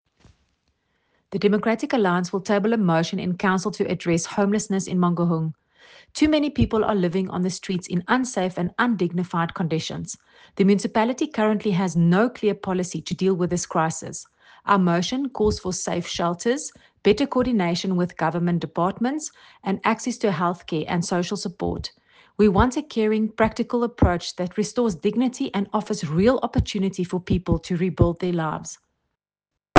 Afrikaans soundbites by Cllr Corize van Rensburg and